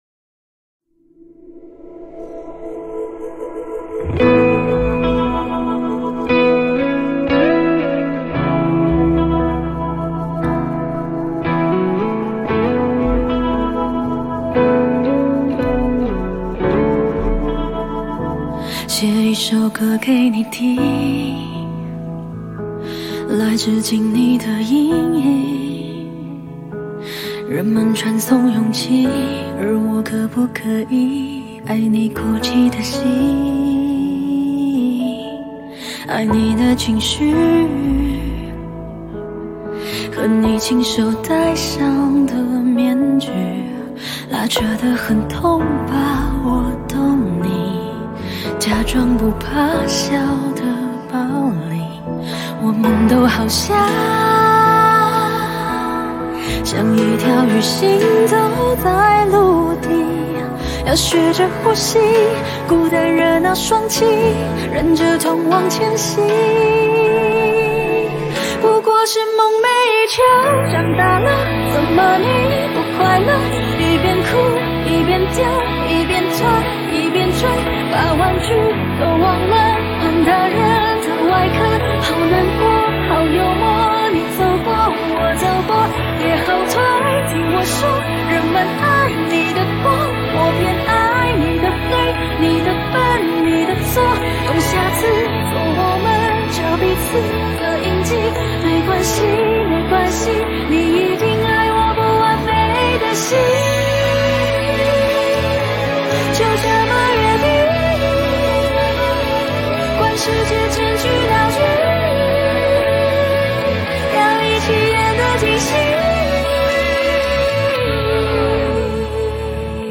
4/4 60以下